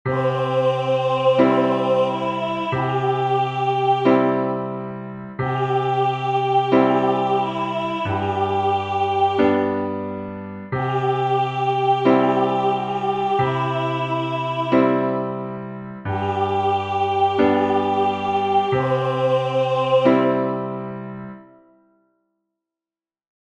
Let's sing voice and piano score
lectura_entonacion_con_acompanamiento_1_UD2.mp3